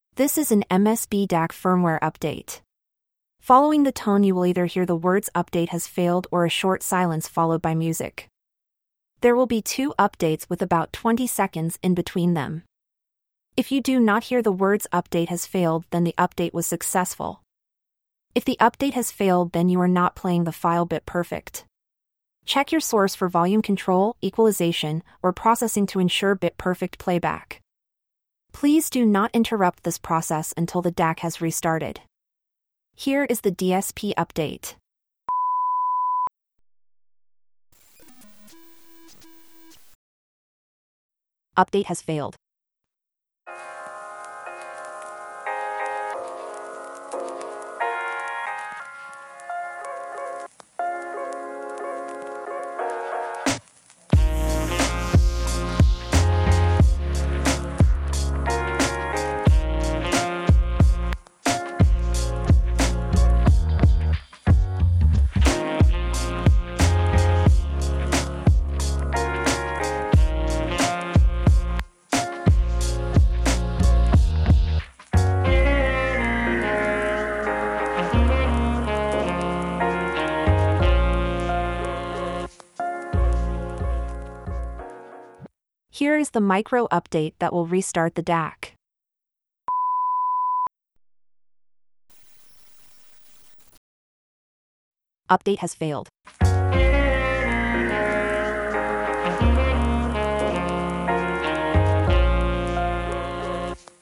When you play the file you will hear instructions and two upgrade tones. Following each tone you will either hear silence for about 30 seconds (this varies) or you will hear the message ‘upgrade failed’.